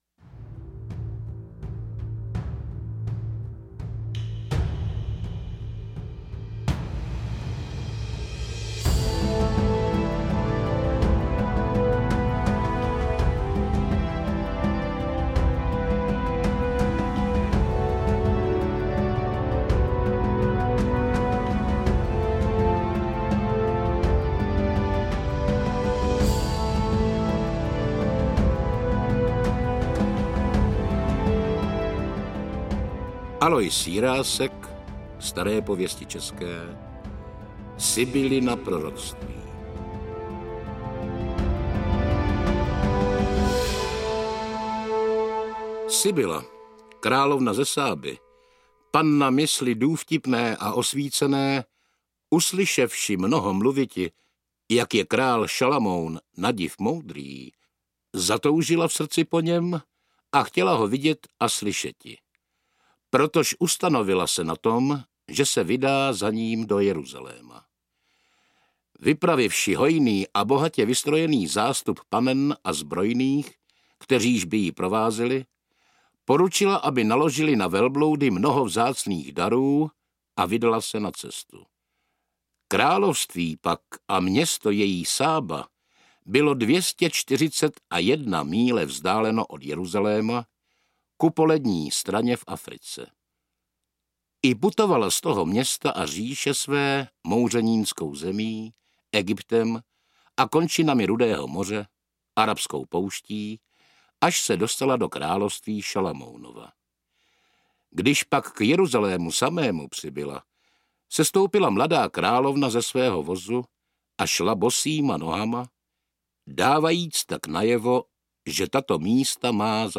Toto je nejprestižnější a nejpůsobivější zvukové zpracování našich národních bájí. Představujeme Vám speciální výběrovou kolekci těch nejlepších legend ze Starých pověstí českých Aloise Jiráska, které jsou i dnes živé, dramatické a mrazivě aktuální!